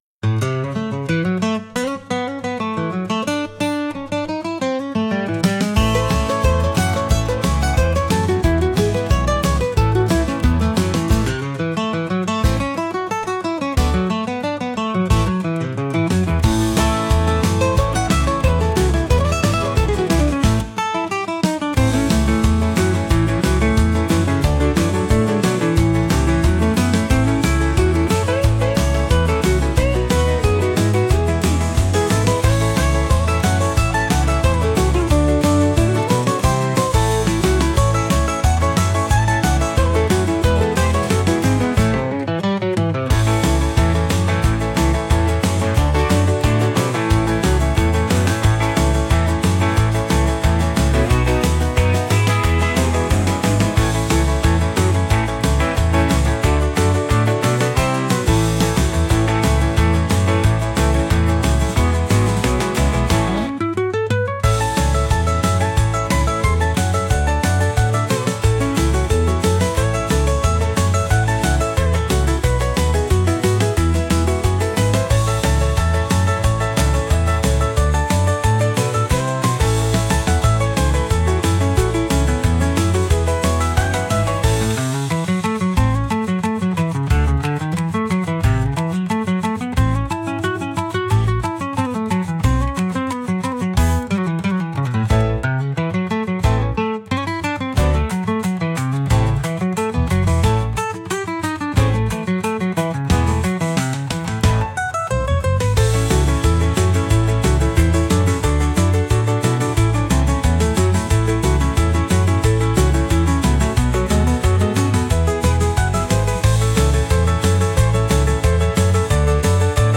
車に乗ってノリノリで出かけるような音楽です。